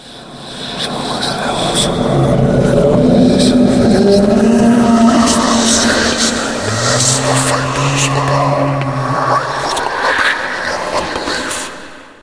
ambient_drone_7.ogg